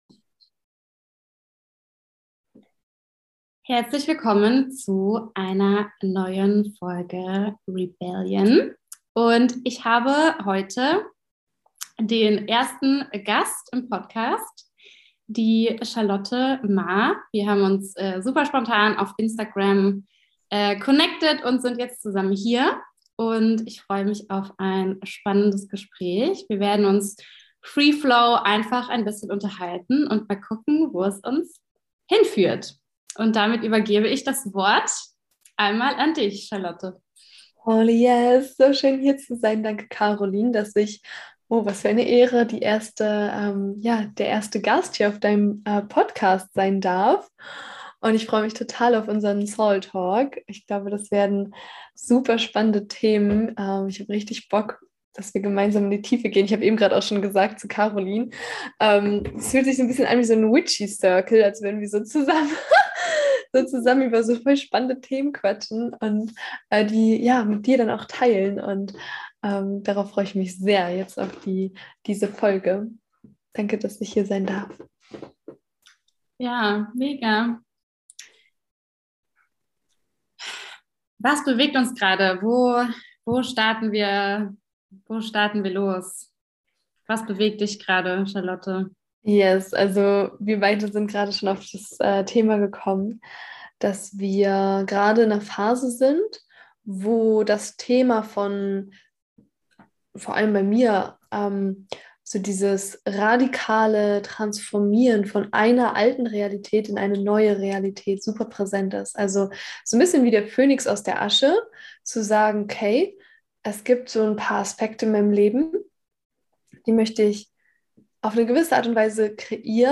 Was dabei rausgekommen ist, ist ein wunderschönes ehrliches, rohes und erdendes Gespräch über das Leben, Manifestieren wie es heute für uns funktioniert und verschiedene Gedanken zur aktuellen Zeit.